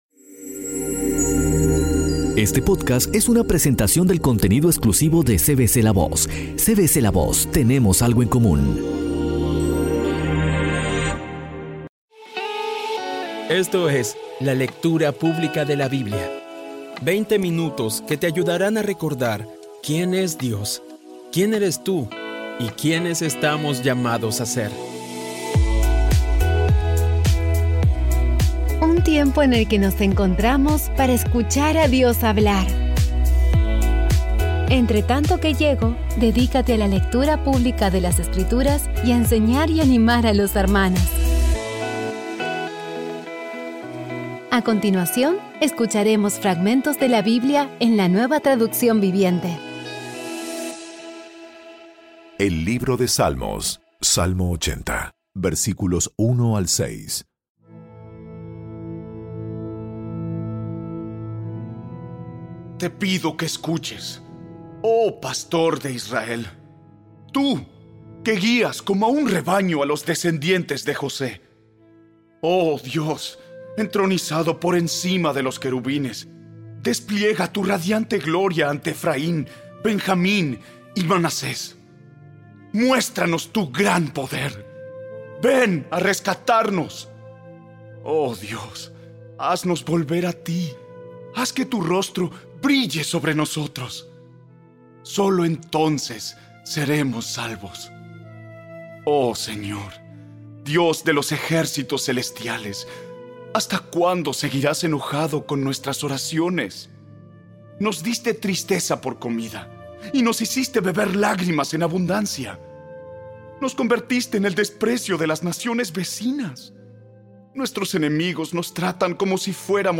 Audio Biblia Dramatizada Episodio 193
Poco a poco y con las maravillosas voces actuadas de los protagonistas vas degustando las palabras de esa guía que Dios nos dio.